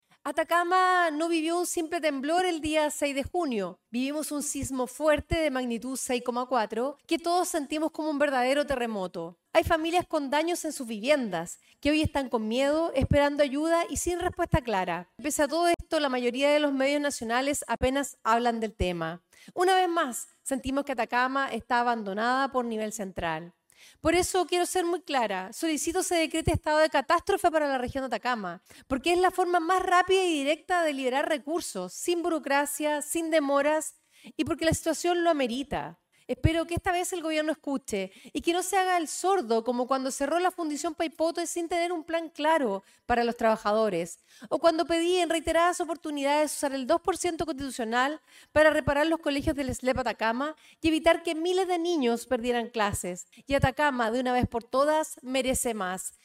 diputada-sofia-cid-intervencion-sismo.mp3